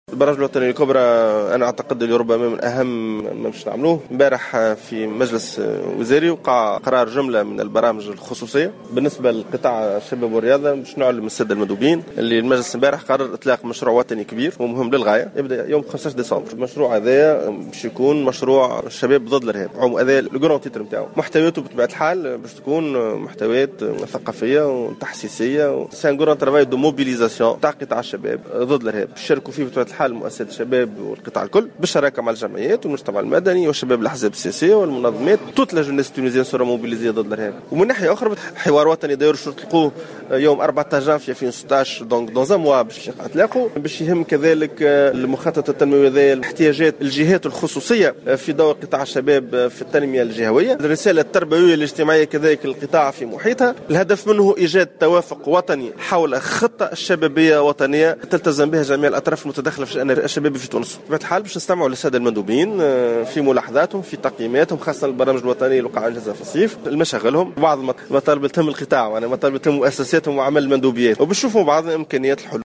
أعلن كاتب الدولة المكلف بالشباب شكري التارزي خلال الملتقى الدوري للمندوبين الجهويين المنعقد اليوم الجمعة 05 ديسمبر 2015 بالحمامات من ولاية نابل أنه تقرر خلال مجلس وزاري عقد أمس إطلاق مشروع وطني "شباب ضد الإرهاب" في 15 ديسمبر المقبل.